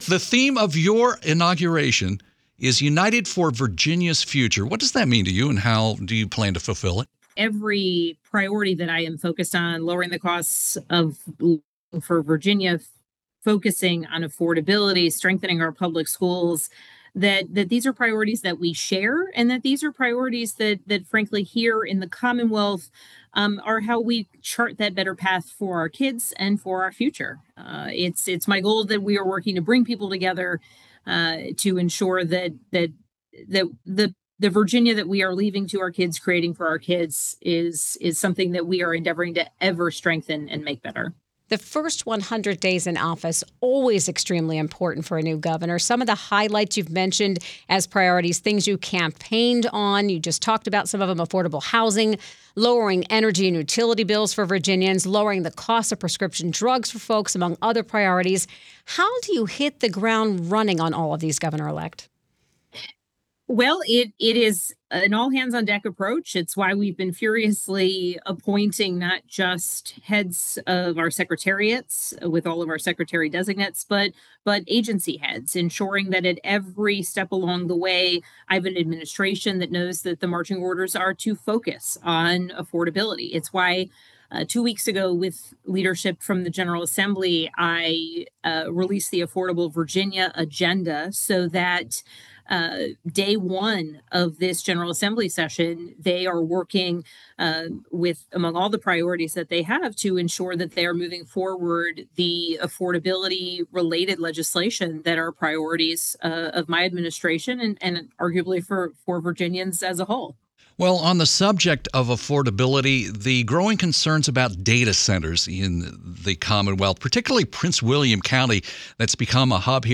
spanberger-full-interview.wav